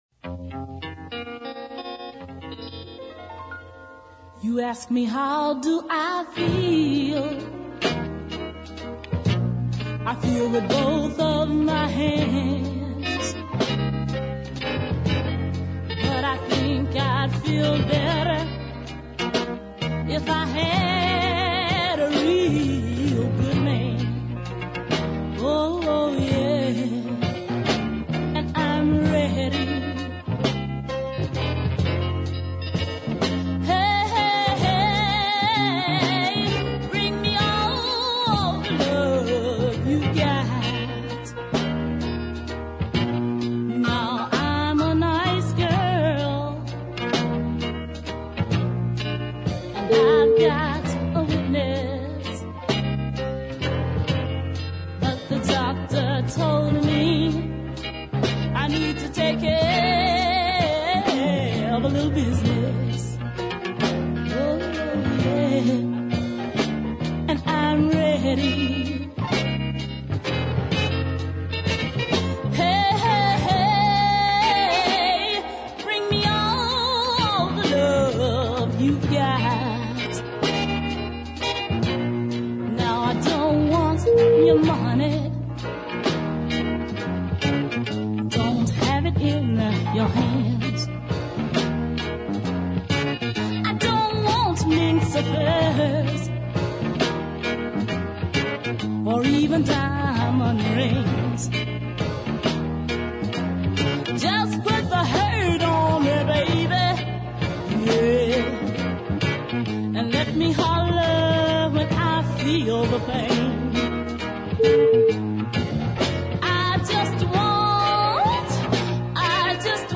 So the bluesy ballad
expressive delivery and fine sense of dynamics